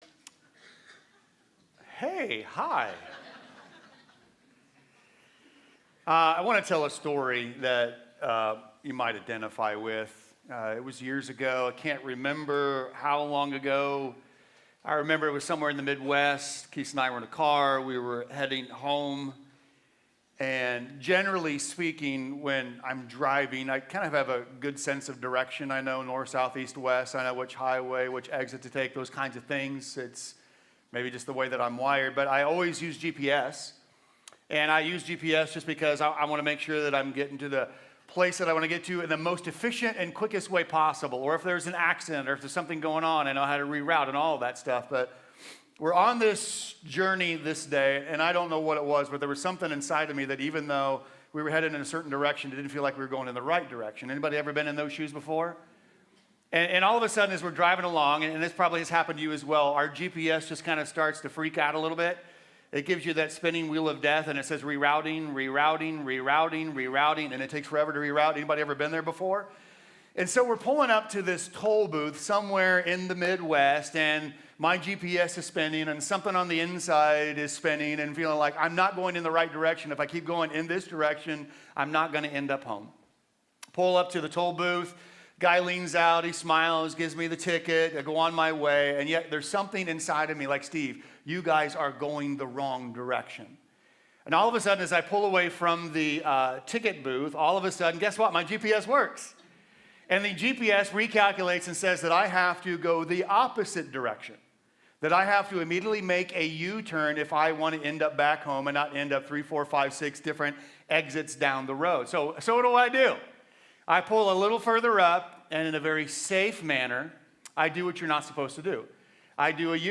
Sermons – Commonway Church